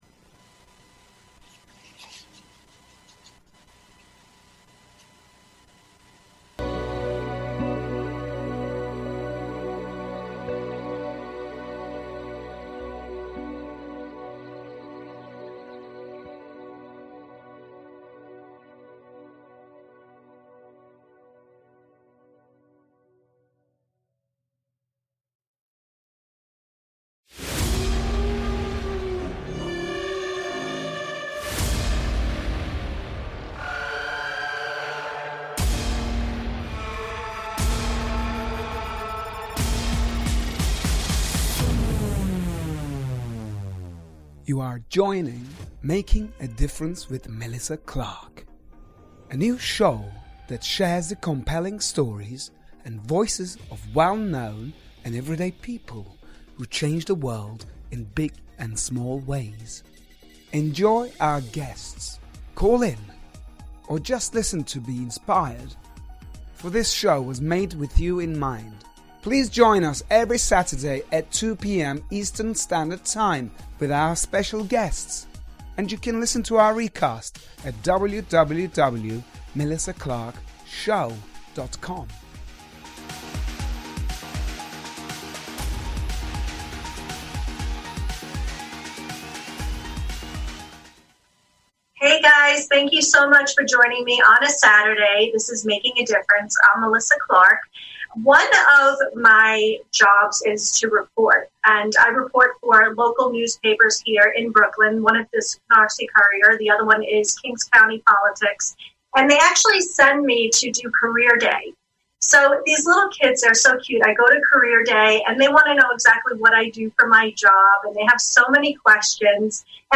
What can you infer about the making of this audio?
Join me in exploring ‘making a difference’…an open conversation and live dialogue with guests, and you our listeners , who have experienced—and embraced—both the demands and benefits of positive change.